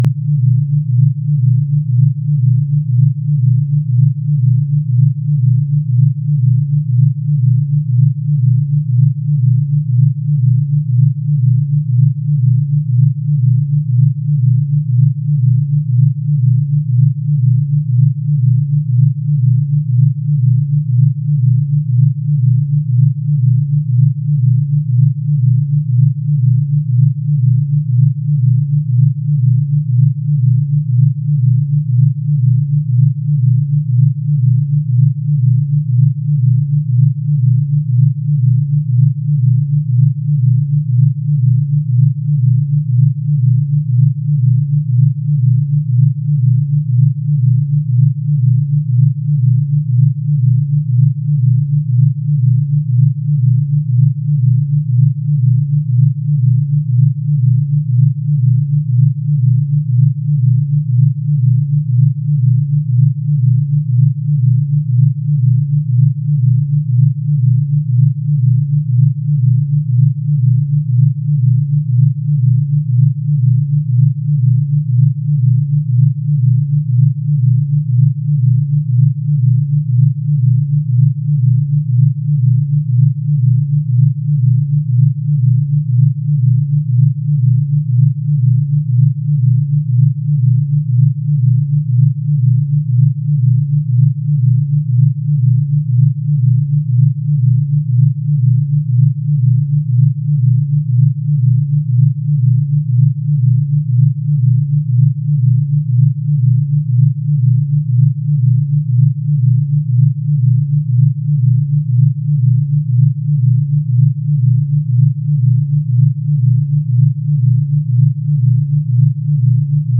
Binaural Beats / 1. Binaural Beats / Materiais Outros MP3 Binaural-Beats-Inteligencia_-Foco_-Memoria-e-Criatividade.mp3 cloud_download